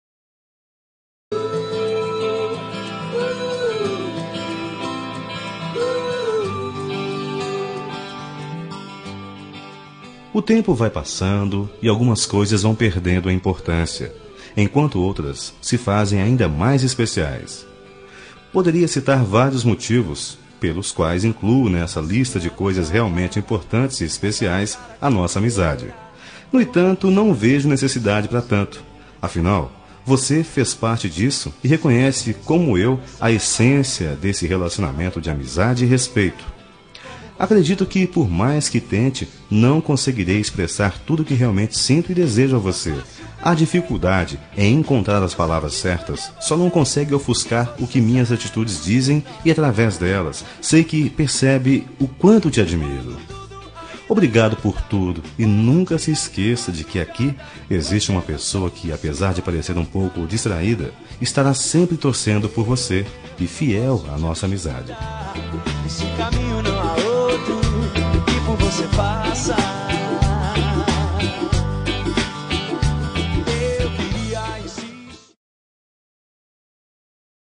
Telemensagem de Amizade – Voz Masculina – Cód: 115